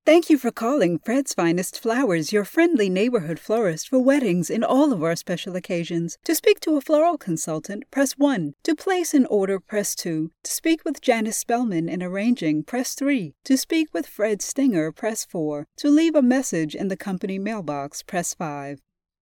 Fred's Finest Flowers IVR
Middle Aged
Female voice talent with a warm, engaging tone, skilled at delivering diverse styles - from friendly commercial ads to authoritative explainer videos.
Experienced in recording from a dedicated home studio, providing quick turnaround times and excellent audio quality.